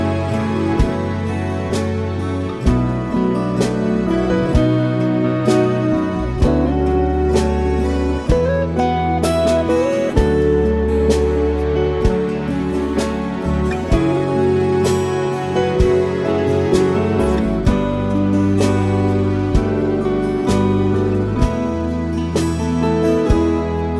Two Semitones Up Jazz / Swing 3:46 Buy £1.50